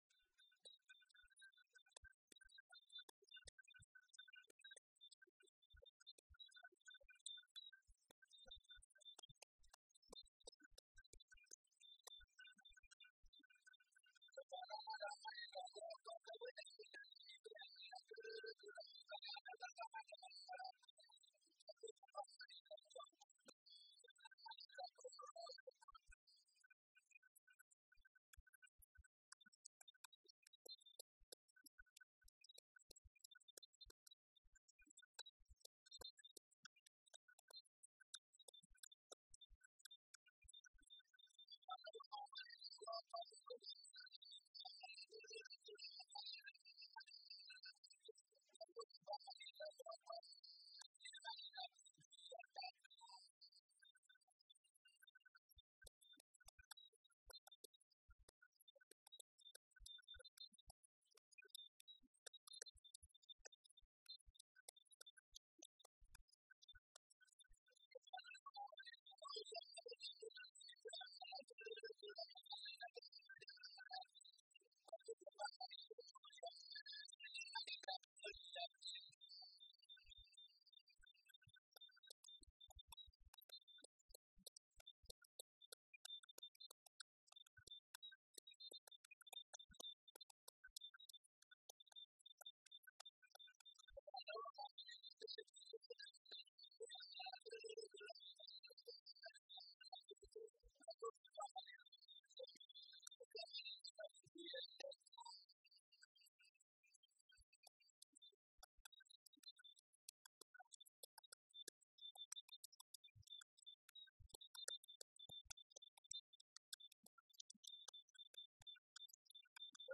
Folk songs, Mangbetu
Field recordings
sound recording-musical
Only one of the two strings of this lute is stopped, the other being used as a kind of drone. A very simple five note accompaniment is possible by this means, from the open string with three stopped notes together with the drone. A bent piece of midrib from a fowl's father is fixed at the far end of the string to act as a buzzer when the string in vibration strikes against it.
Both words and instrumental accompaniment are clearly heard in this recording. Topical song with Nenjenje lute, two strings.